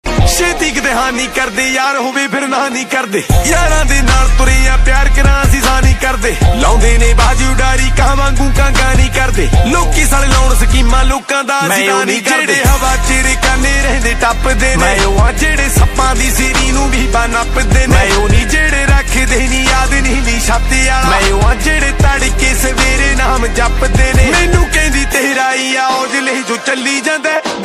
punjabi song ringtone